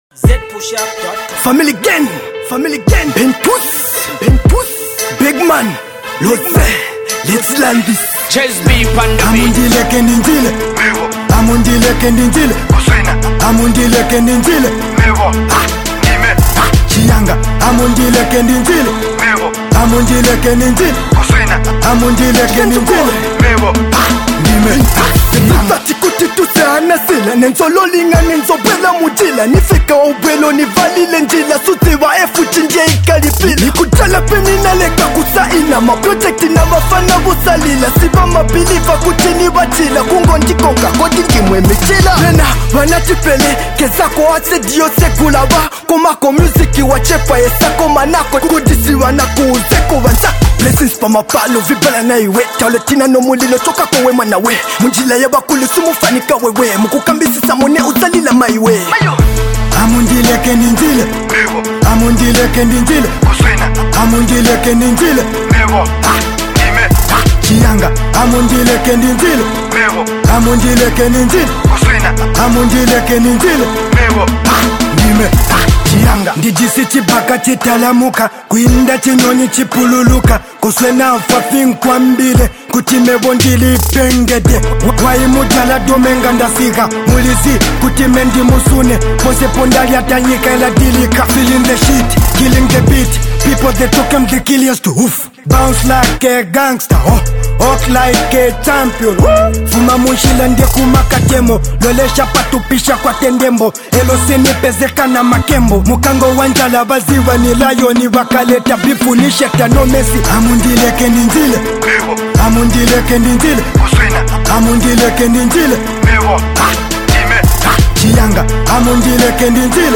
tonga trap joint